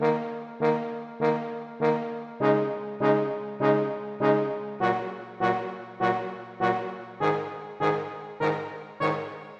标签： 华尔兹 铜管 大号 吉他 圣诞 节日
声道立体声